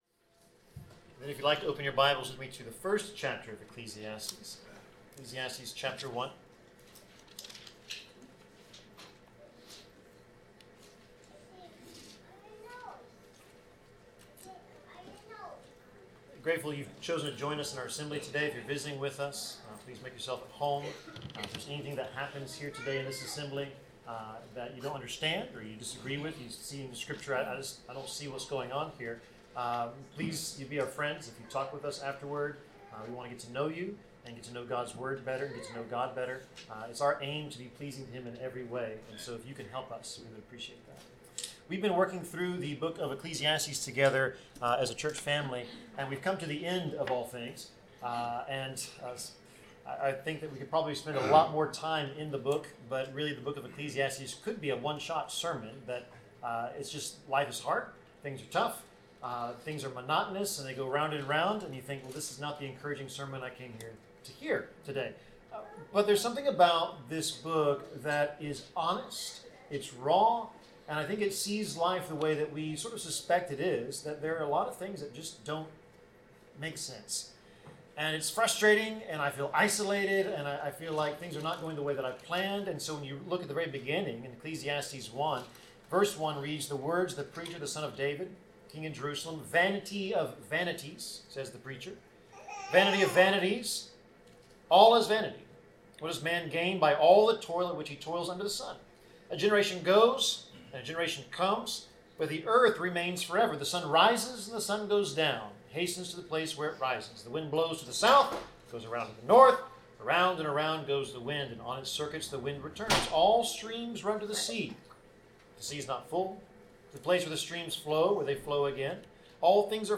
Passage: Ecclesiastes 12:9-14 Service Type: Sermon